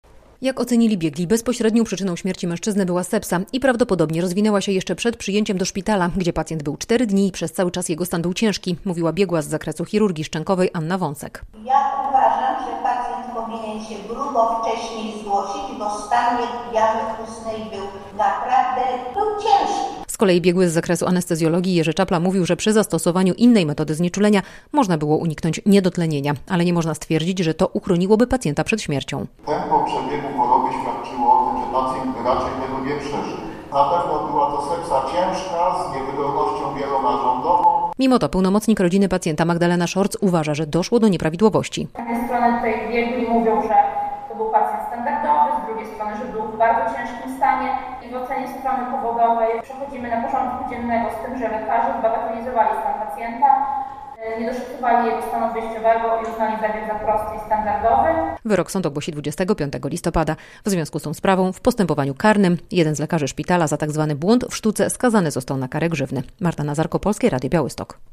Radio Białystok | Wiadomości | Wiadomości - Zakończył się proces o zadośćuczynienie dla rodziny pacjenta, który zmarł w białostockim szpitalu
relacja